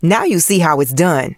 maisie_lead_vo_01.ogg